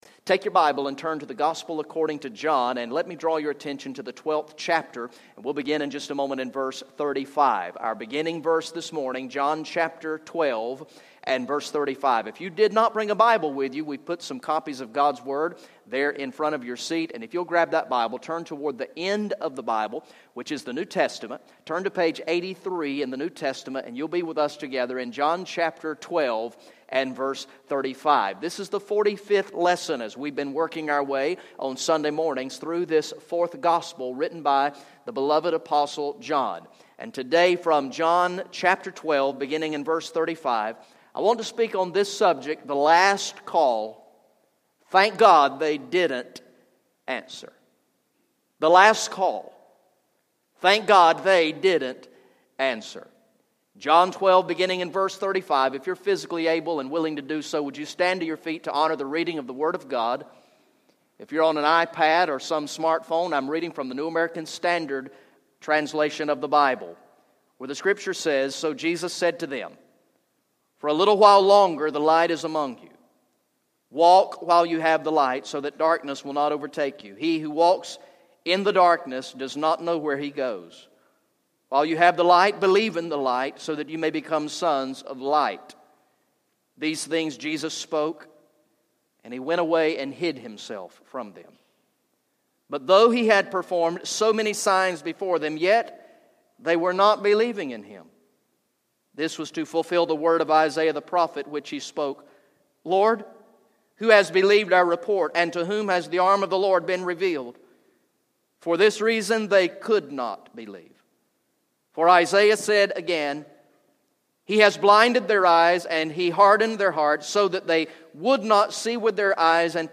Message #45 from the sermon series through the gospel of John entitled "I Believe" Recorded in the morning worship service on Sunday, October 18, 2015